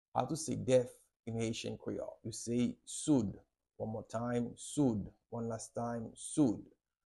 How to say "Deaf" in Haitian Creole - "Soud" pronunciation by a native Haitian Teacher
“Soud” Pronunciation in Haitian Creole by a native Haitian can be heard in the audio here or in the video below: